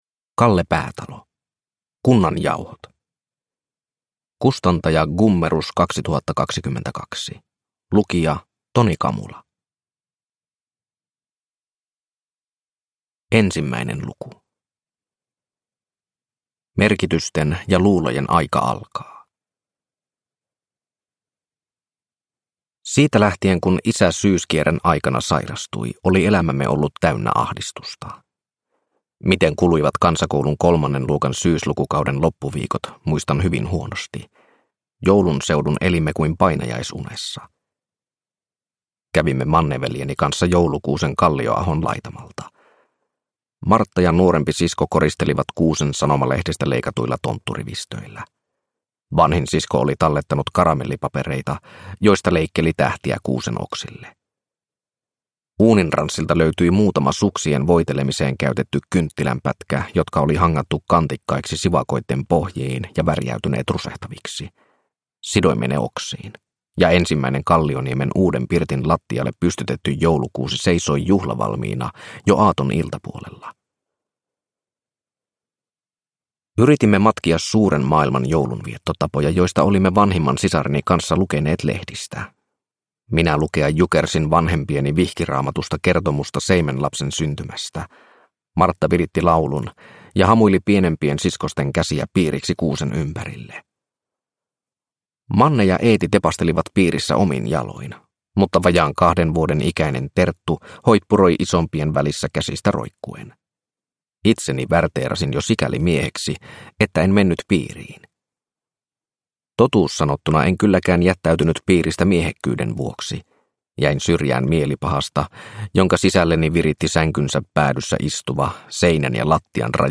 Kunnan jauhot – Ljudbok – Laddas ner